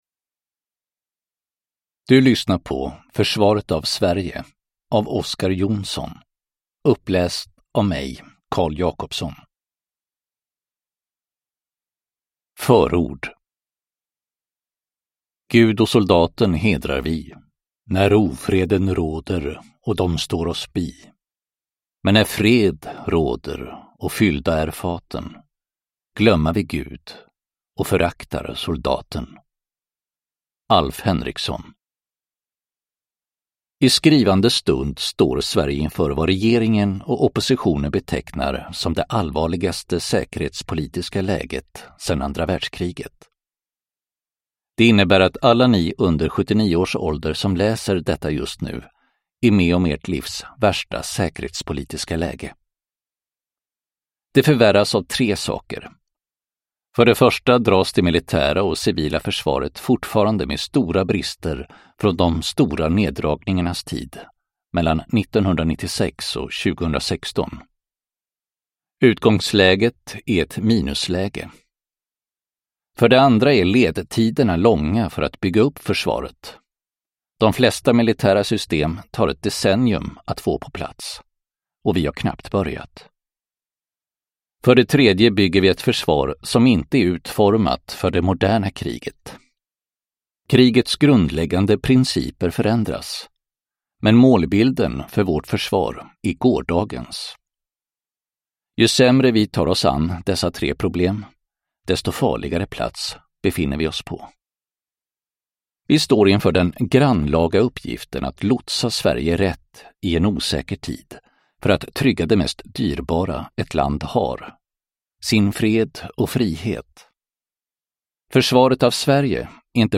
Försvaret av Sverige (ljudbok) av Oscar Jonsson